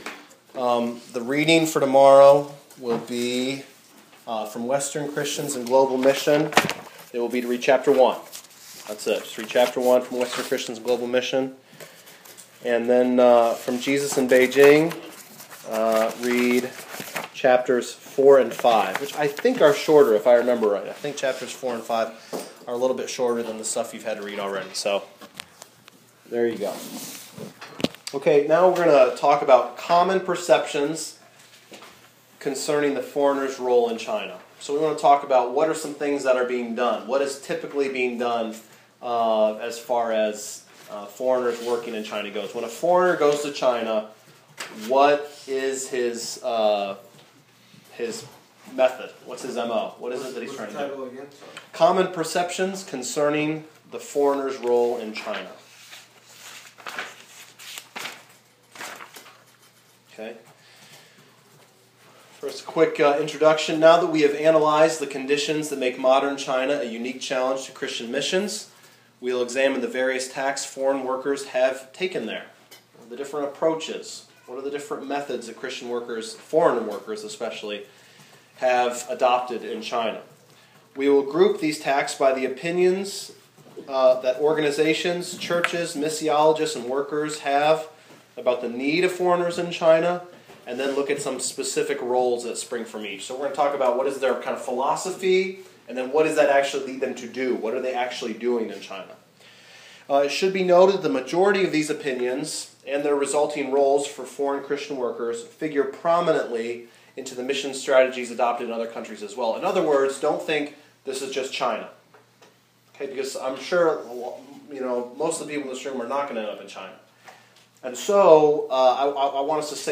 Recently I had the privilege to teach a modular course at the Our Generation Training Center in Georgia.
lecture-2.m4a